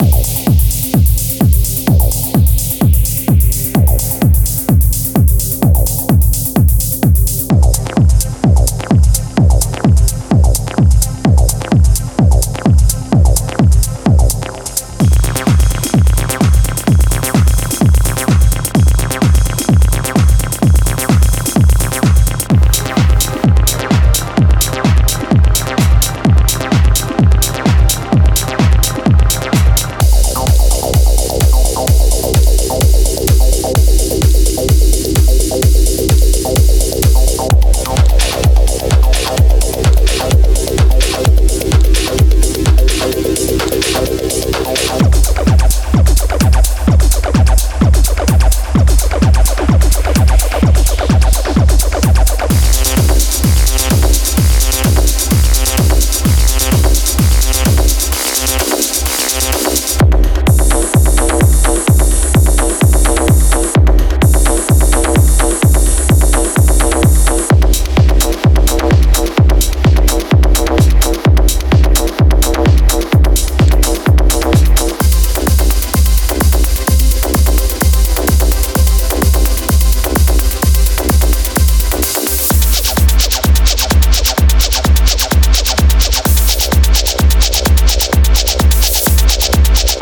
Minimal、Tech-House 和 Techno 音乐。微调、触感十足的鼓点和留白处理，赋予音乐独特的韵味。